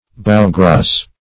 Search Result for " bowgrace" : The Collaborative International Dictionary of English v.0.48: Bowgrace \Bow"grace`\, n. (Naut.) A frame or fender of rope or junk, laid out at the sides or bows of a vessel to secure it from injury by floating ice.